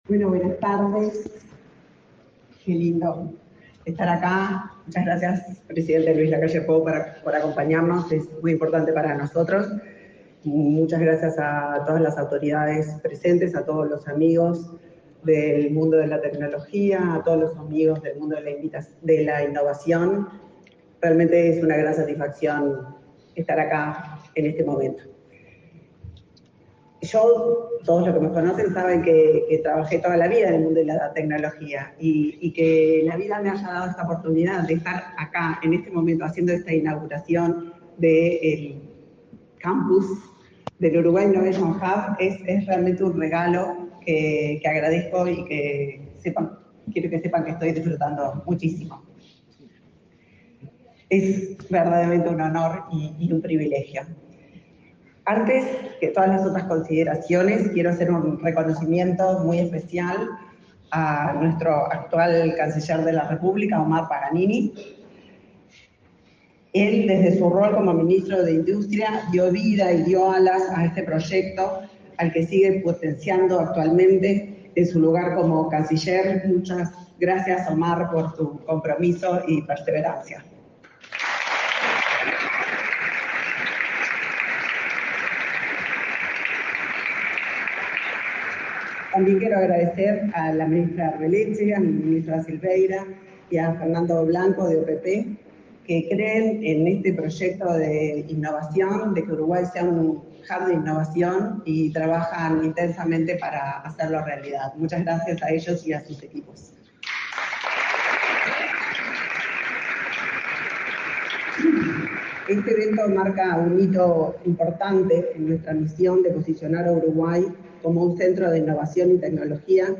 Acto por la inauguración del campus de innovación de Uruguay Innovation Hub